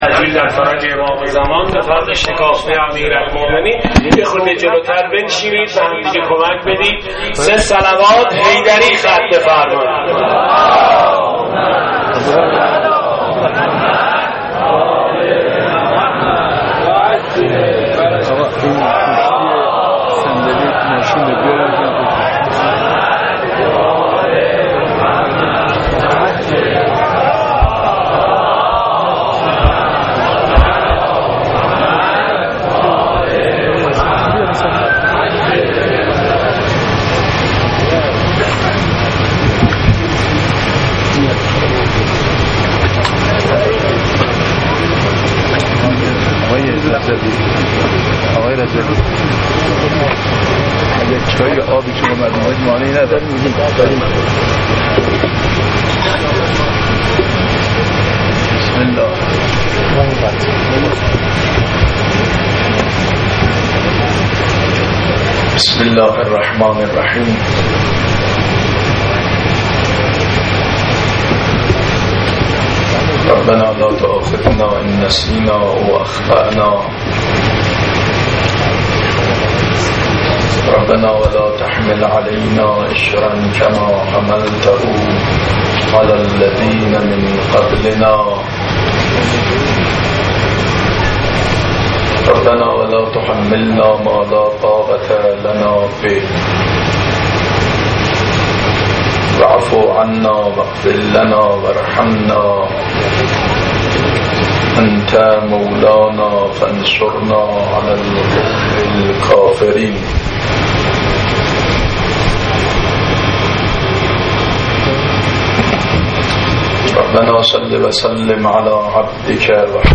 احیای شبه 21 فقط منبر